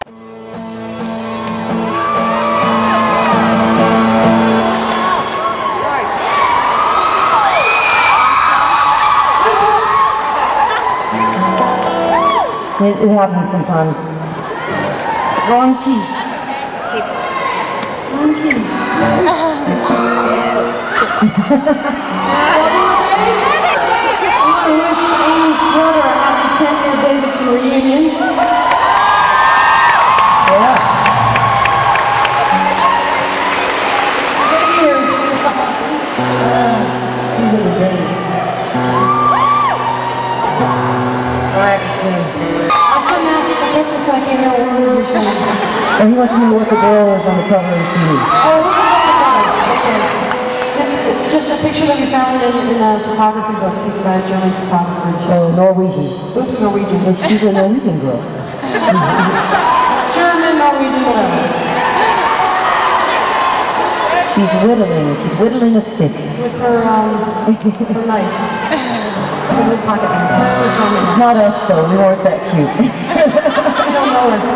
lifeblood: bootlegs: 1999-10-02: john m. belk arena - davidson, north carolina
11. talking with the crowd (1:14)